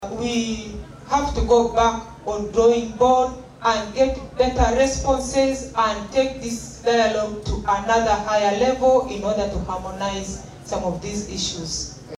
Driwaru Jenifer Maracha District Woman MP.mp3